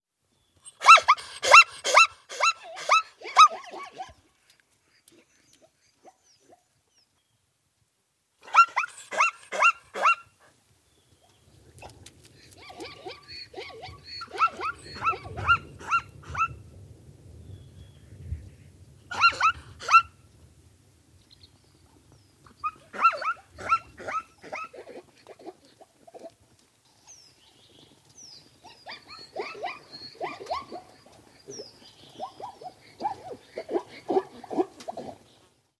Звуки зебры
Смех зебры в бескрайней саванне